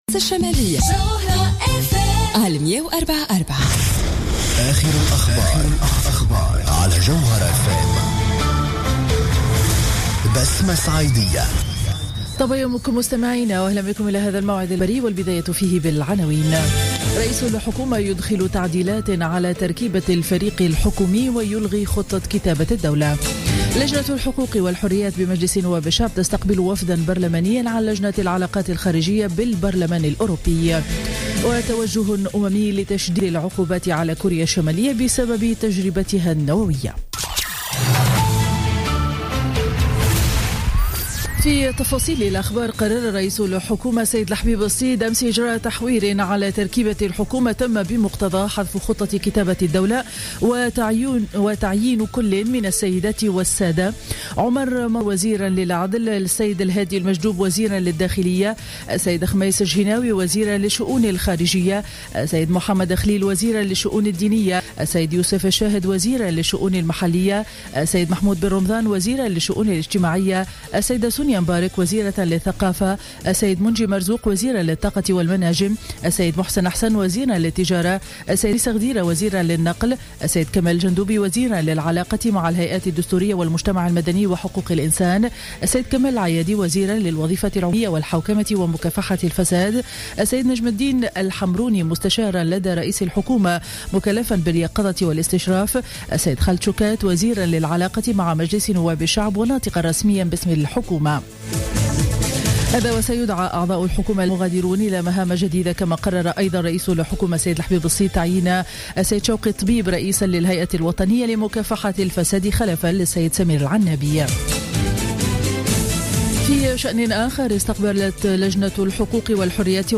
نشرة أخبار السابعة صباحا ليوم الخميس 07 جانفي 2015